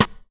• Classic Clear Hip-Hop Snare Drum Sample G# Key 32.wav
Royality free snare tuned to the G# note. Loudest frequency: 1791Hz
classic-clear-hip-hop-snare-drum-sample-g-sharp-key-32-7tv.wav